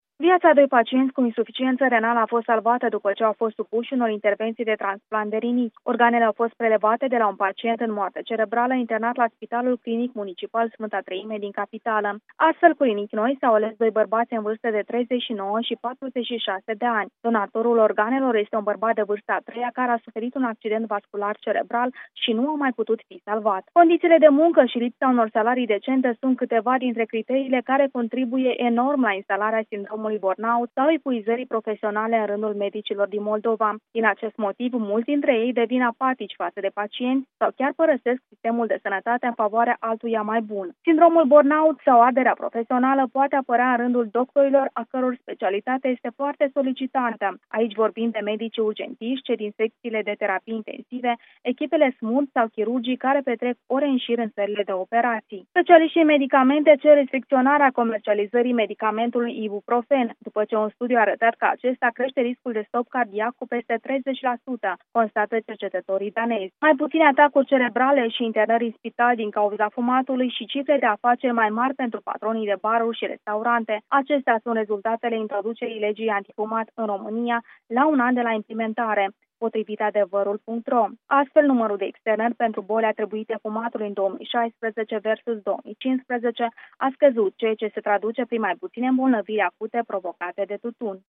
Invitata de astăzi